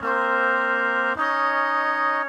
GS_MuteHorn_105-CD#.wav